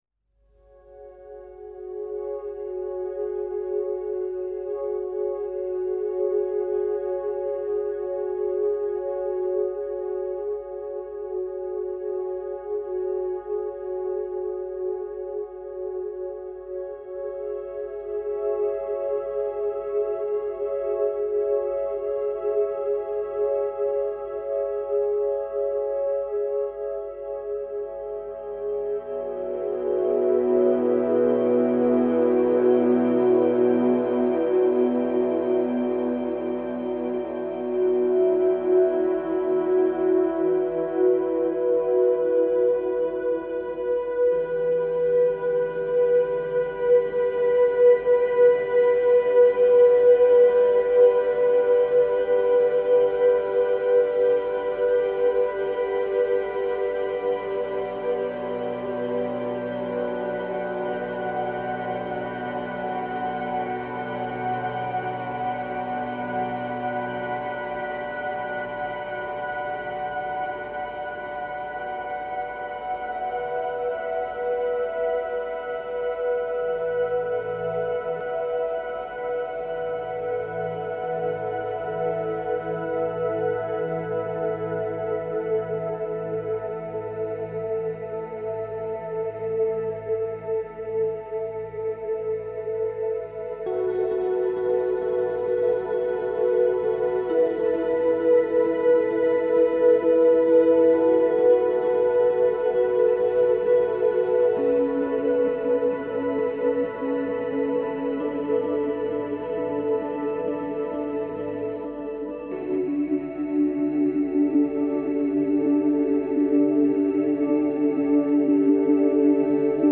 synthetiseur - sound design - profondeurs - aerien - ciel